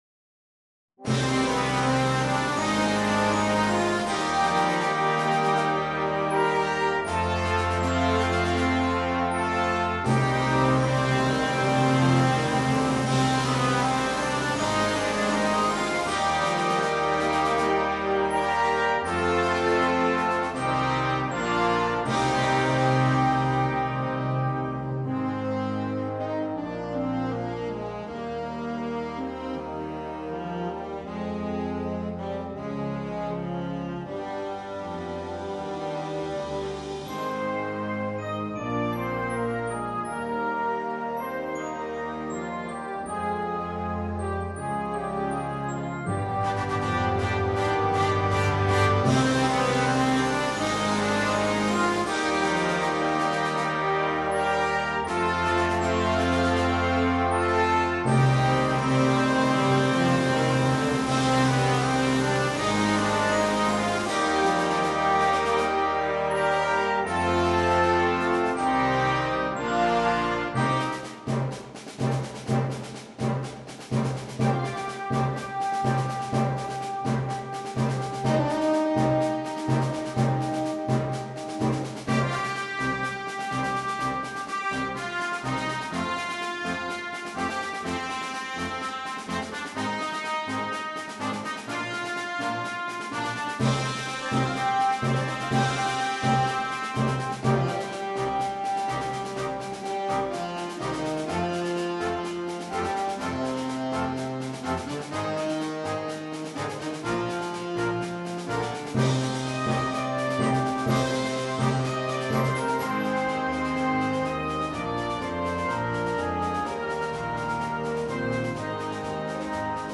Per banda
Una bella composizione che ci riporta nel vecchio West.